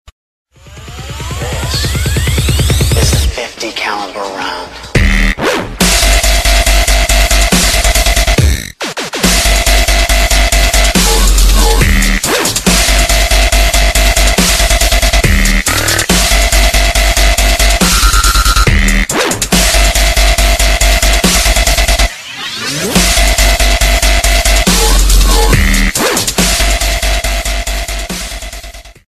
D'n'B & Jungle